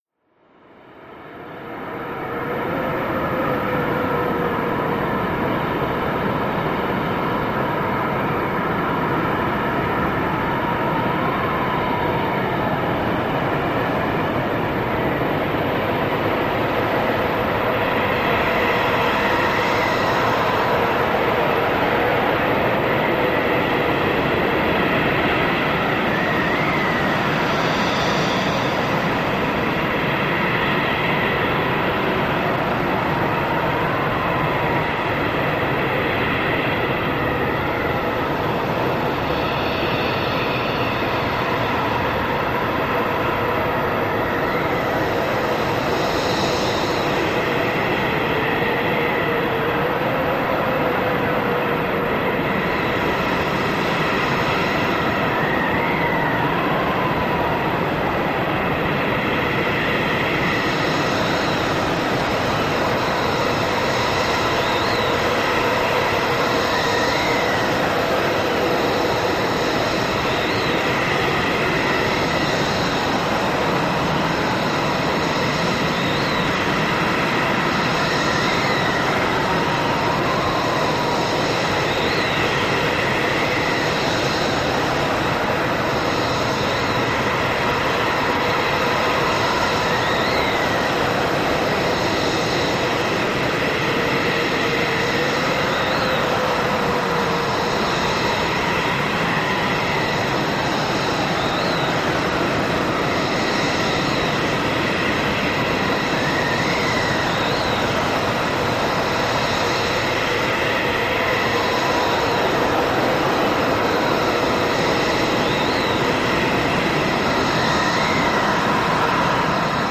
Hurricanes
HurricaneWindHowl AHS027801
Hurricane Wind, Howling Tone, Very High Velocity, Stereo Panning